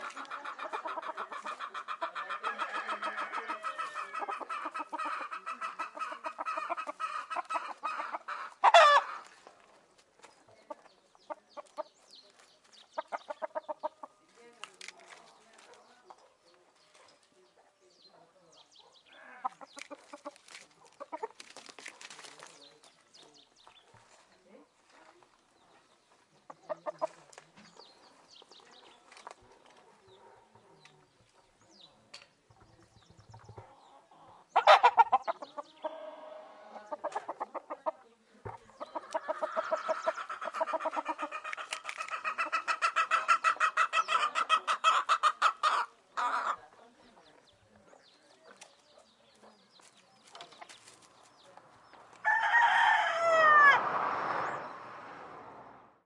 描述：hen clucking. PCM M10 internal mics, recorded near Utiaca, Gran Canaria
标签： chicken clucking fieldrecording hen henhouse
声道立体声